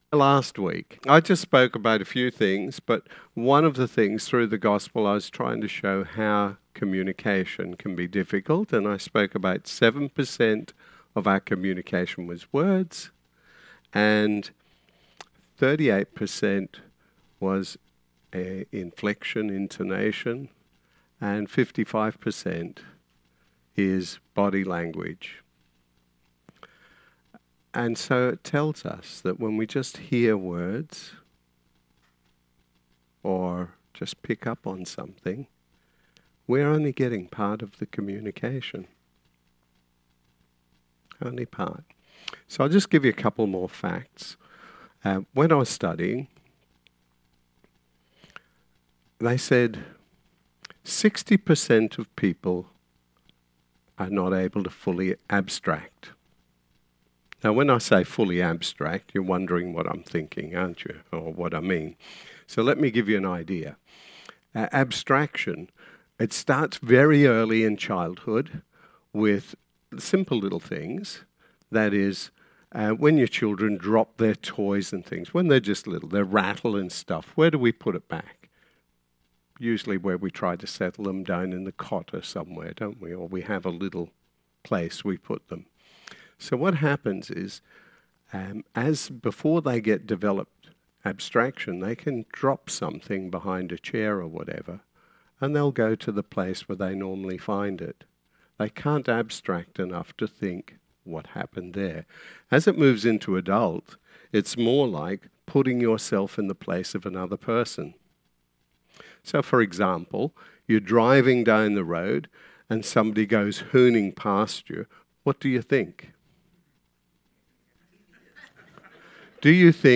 Sermon 15th September